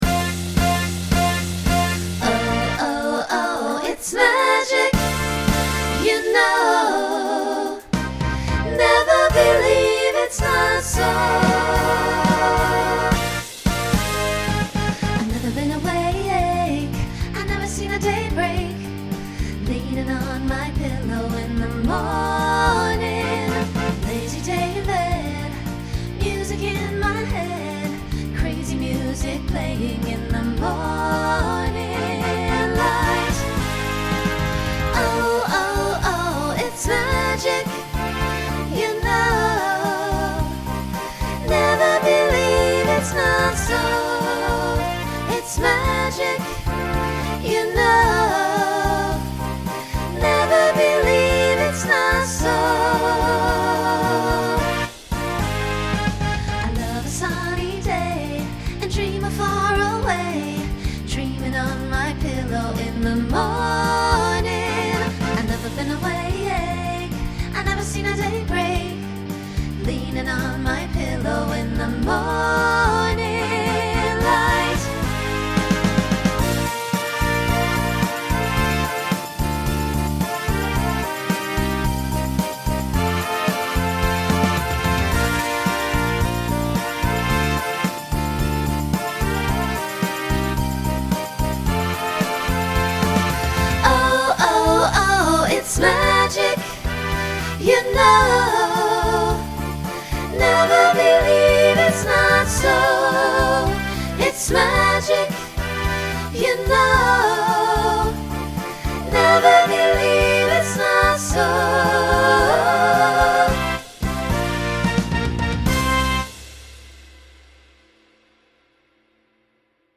Genre Pop/Dance Instrumental combo
Mid-tempo , Opener Voicing SSA